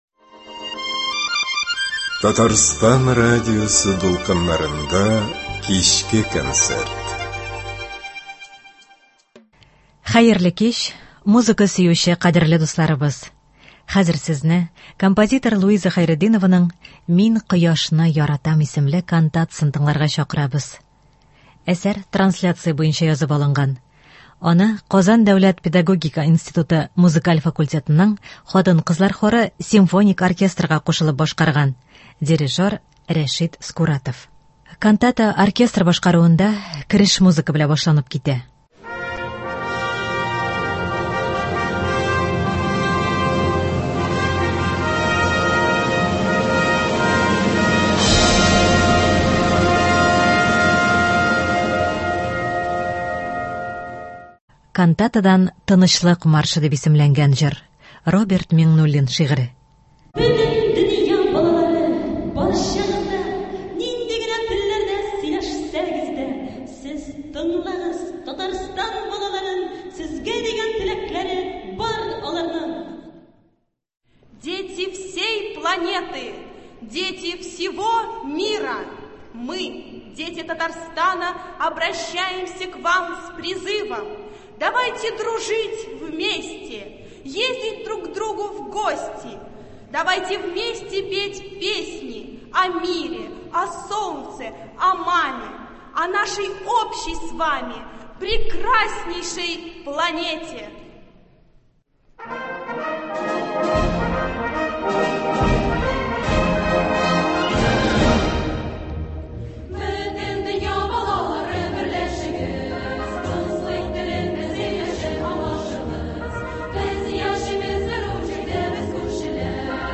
Кантата.